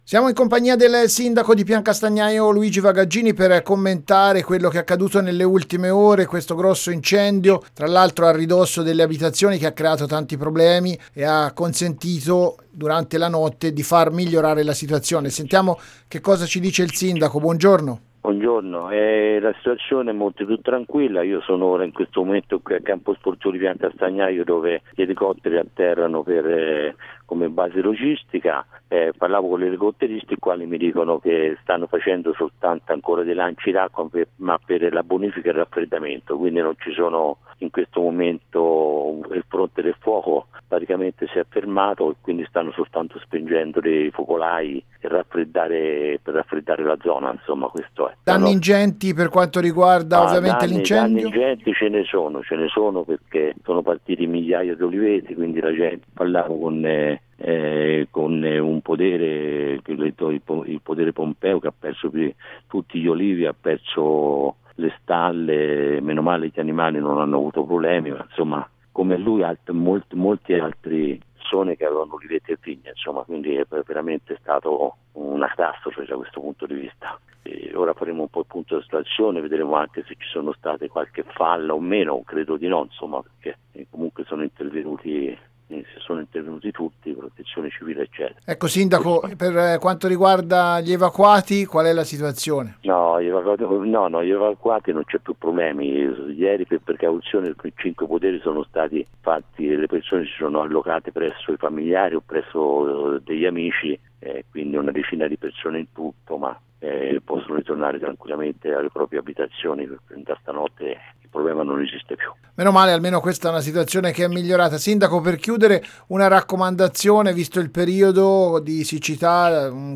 Grosso incendio a Piancastagnaio: il sindaco Luigi Vagaggini - Antenna Radio Esse